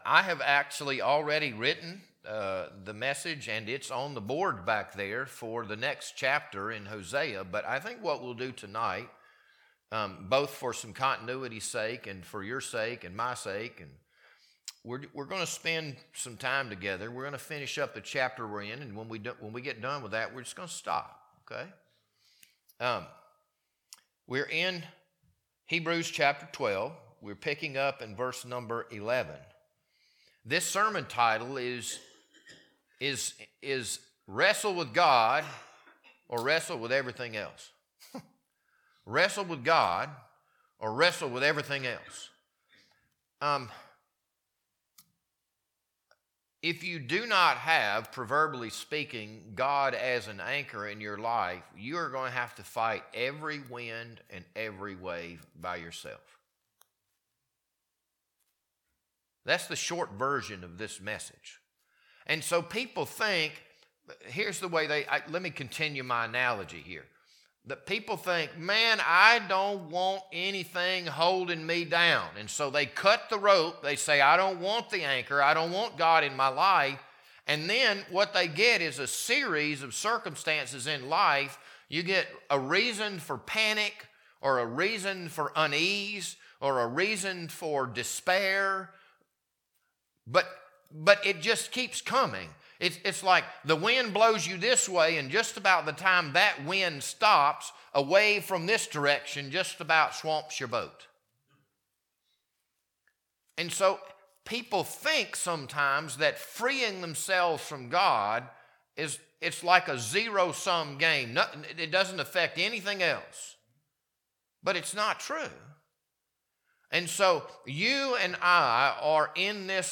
This Sunday evening sermon was recorded on February 22nd, 2026.